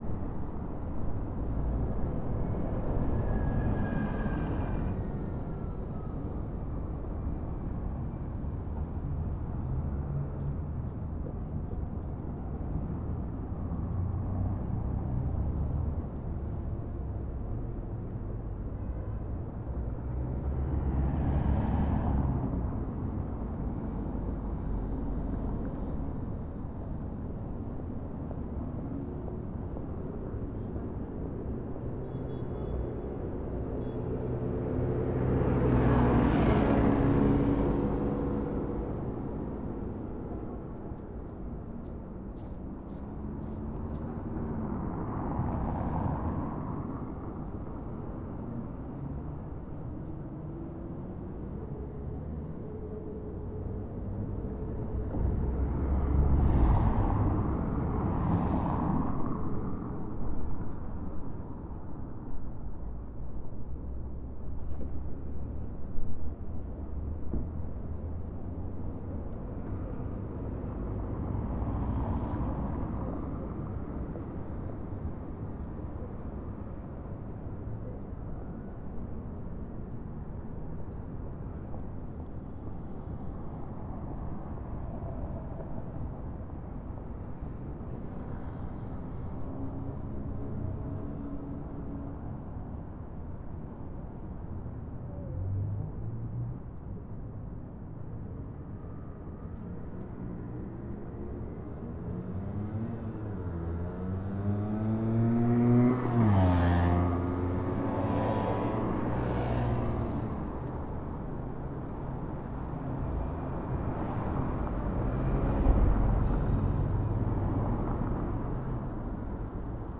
Paris_stret_large2_rolloff.wav